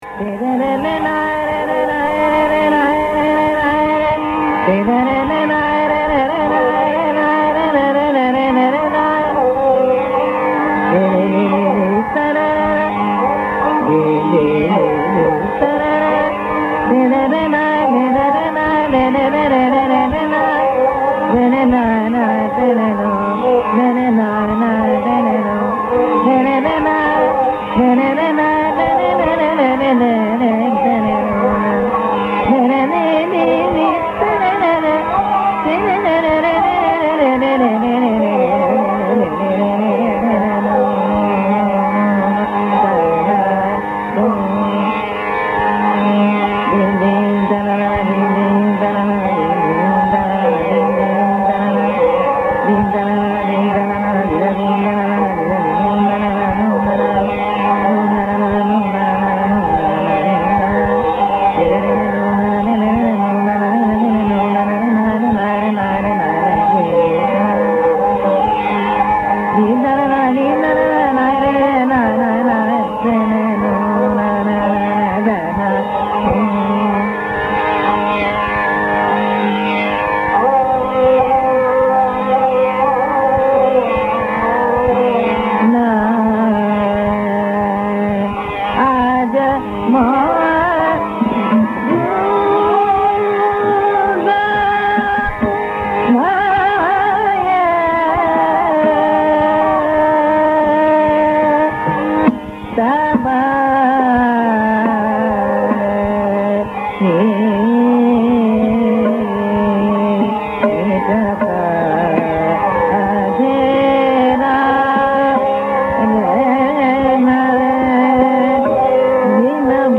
Ragas of Indian Classical Music.
Indian Classical Music